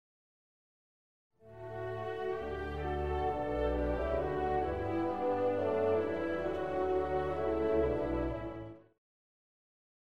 Beethoven's Symphony No. 9 in D minor, Op. 125, also known as "The Choral."
If you listen to the music again you'll hear that the rhythm goes like this:
Tah-tah-tah-tah-tah-tah-tah-tah-tah-tah-tah-tah-taaah-ta-ta.